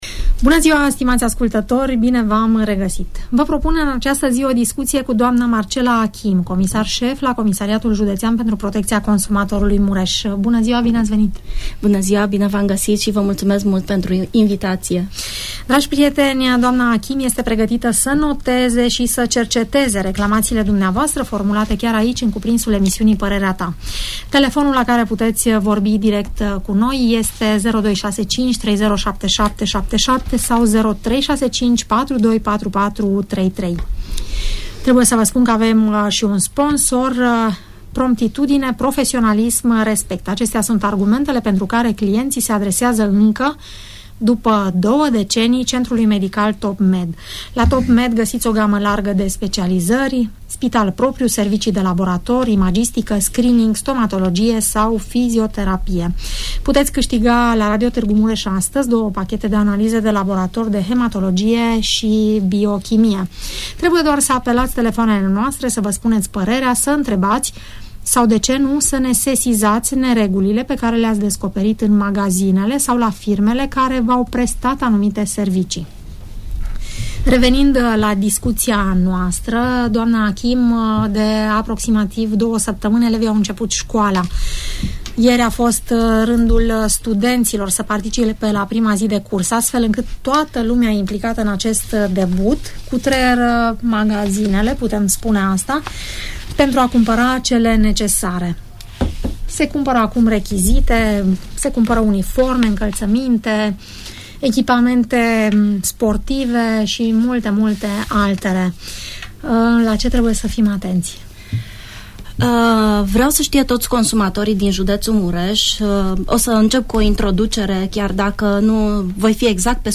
La " Parerea ta" a fost invitata dna Marcela Achim, comisar sef al OPC Mures. S-a vorbit despre controalele efectuate la agentii economici care vand rechizite precum si la cei care detin magazine alimentare in incinta scolilor sau in vecinatatea acestora